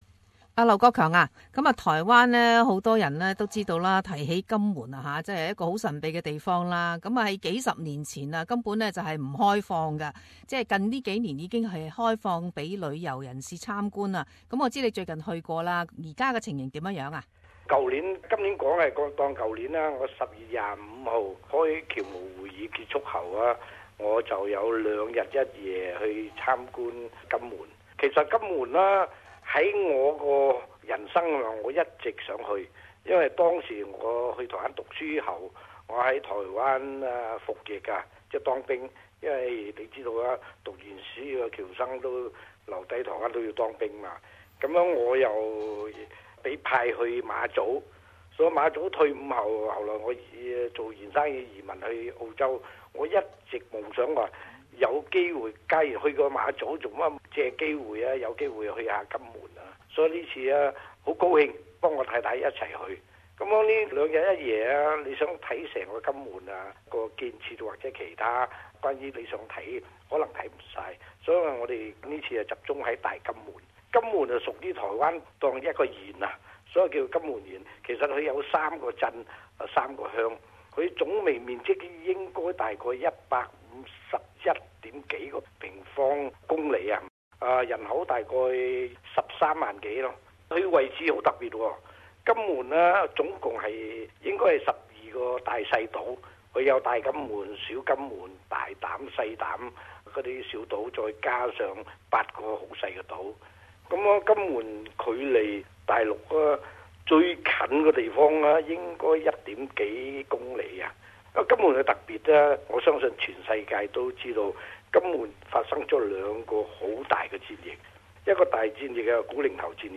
社团专访 - 金门解除神秘换上新面貌